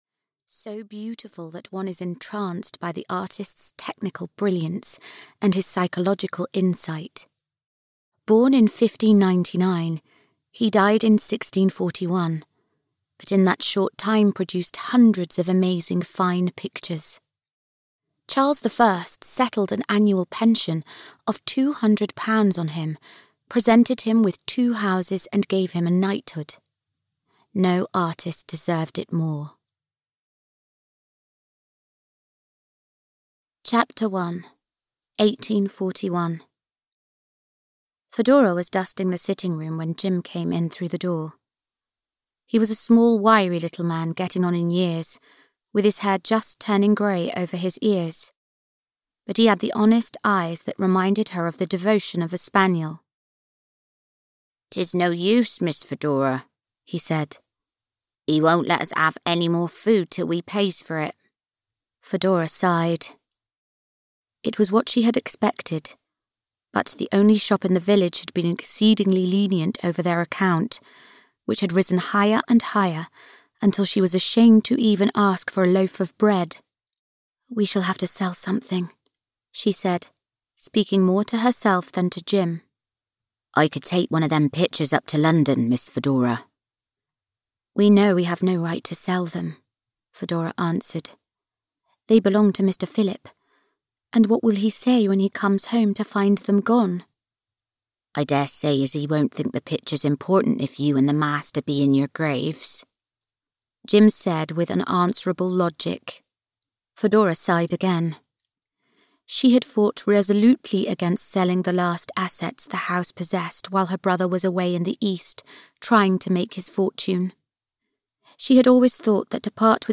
A Portrait of Love (EN) audiokniha
Ukázka z knihy
• InterpretKathryn Drysdale